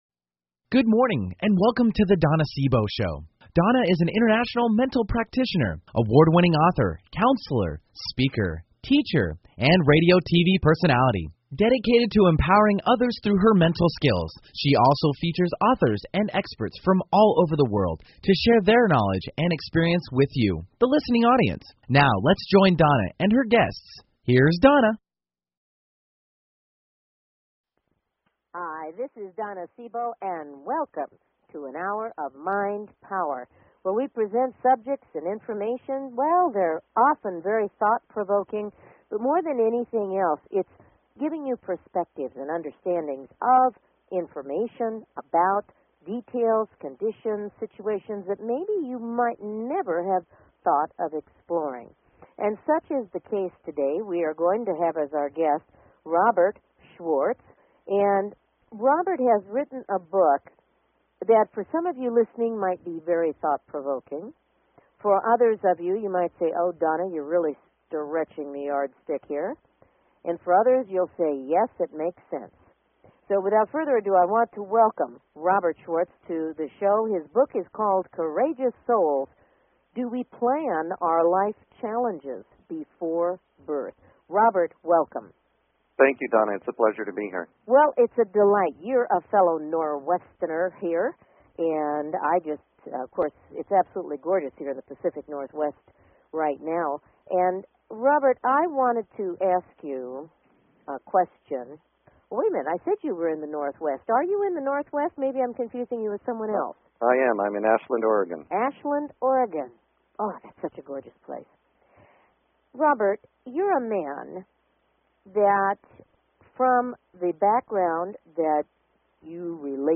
Have you ever wanted to explore the possibility of reincarnation? This interview will share some thoughtful perspectives to consider.